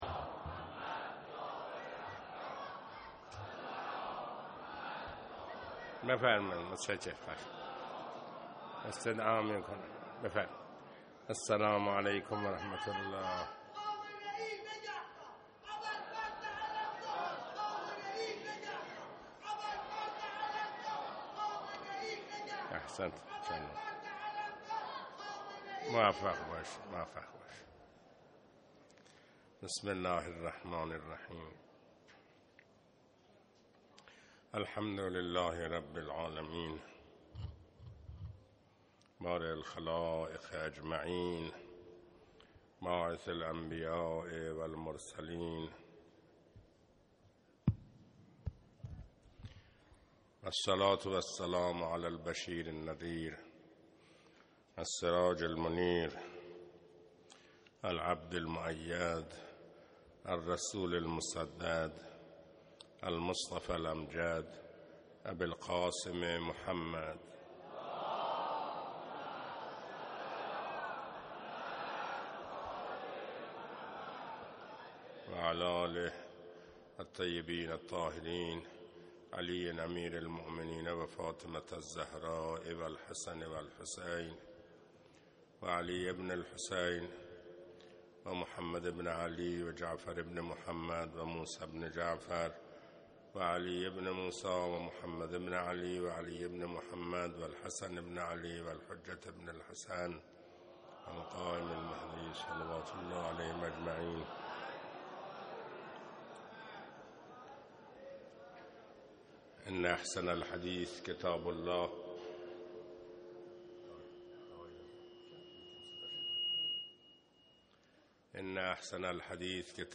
خطبه اول